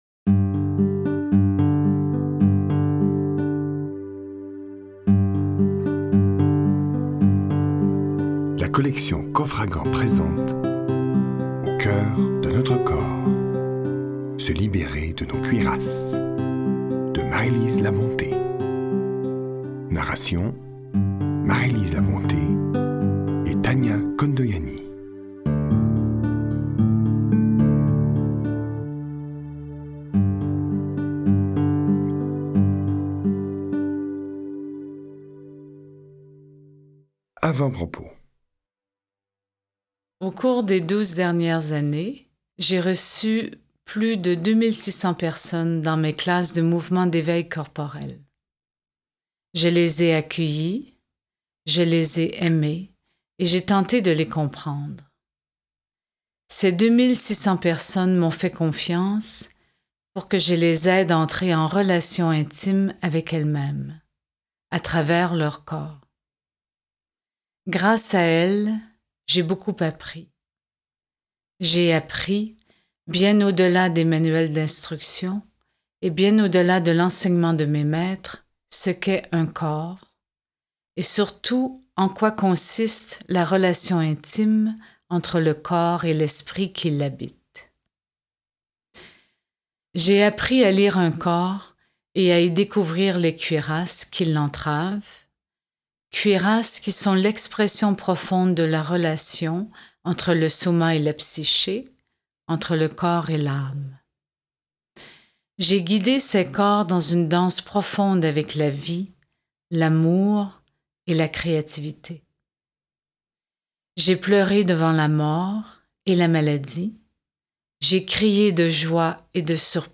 Click for an excerpt - Au coeur de notre corps de Marie-Lise Labonté